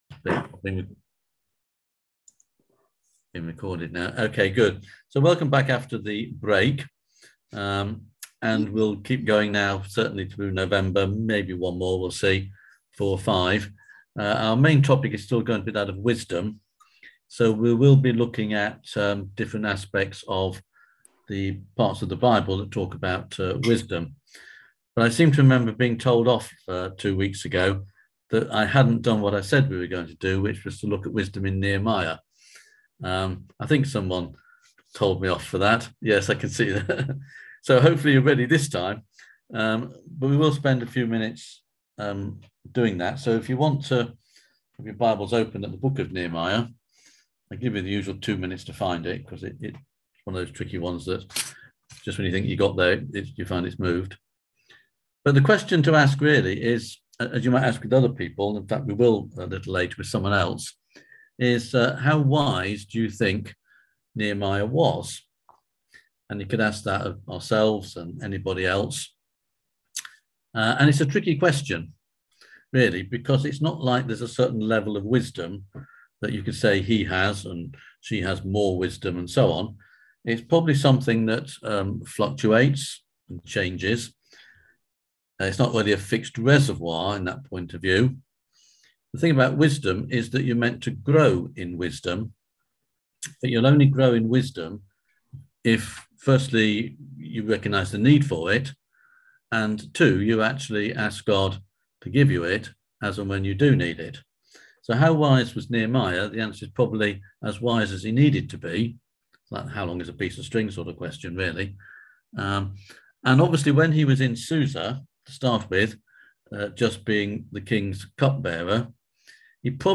On November 3rd at 7pm – 8:30pm on ZOOM